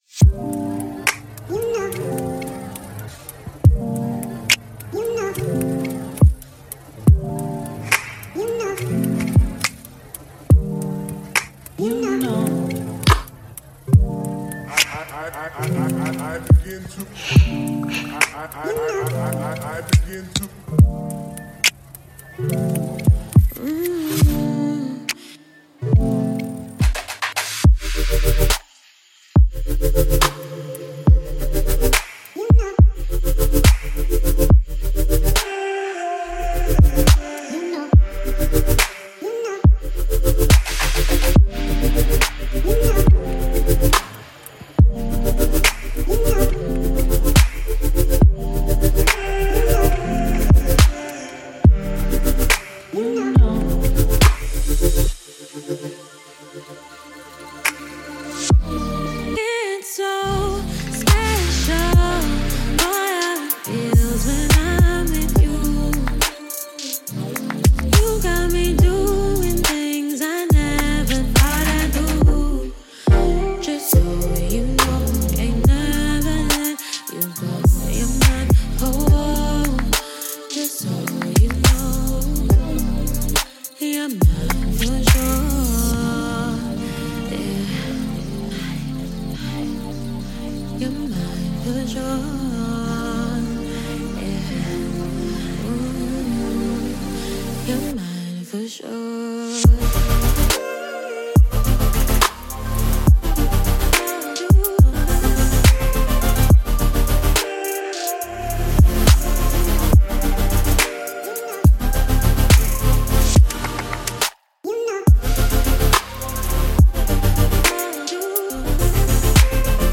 это захватывающая композиция в жанре R&B